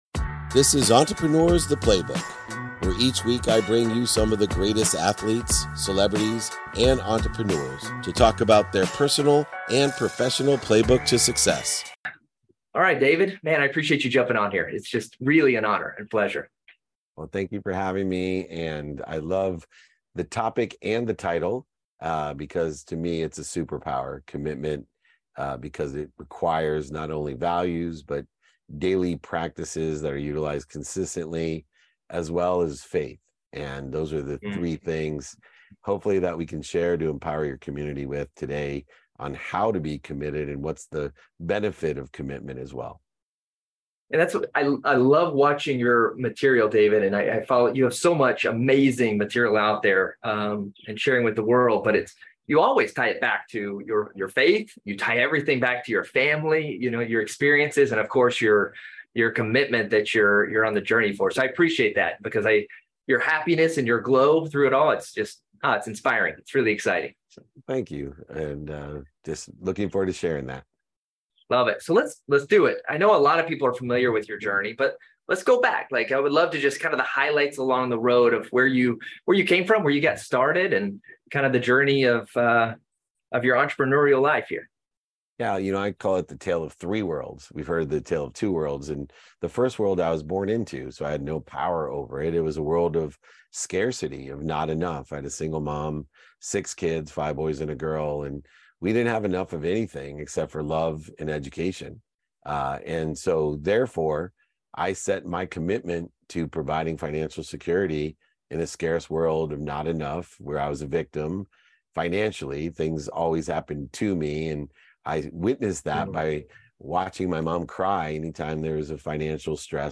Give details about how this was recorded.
Today's episode is from a conversation on the Committed Podcast